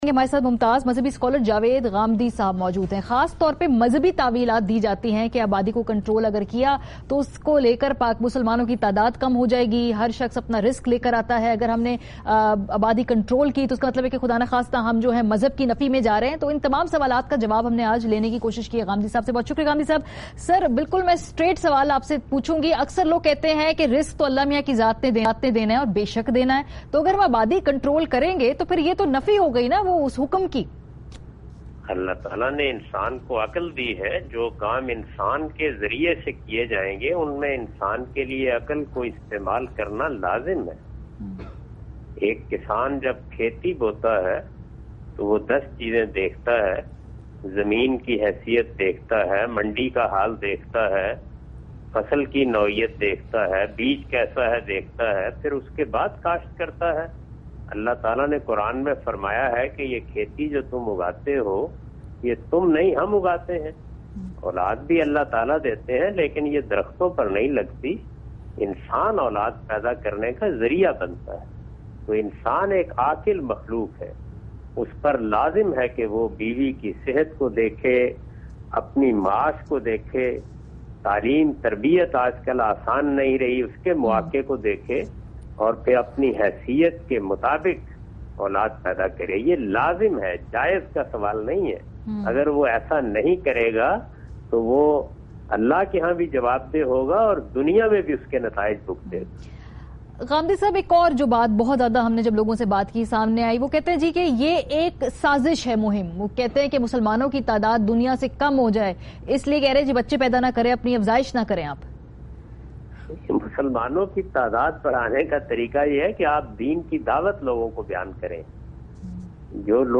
Category: TV Programs / ARY /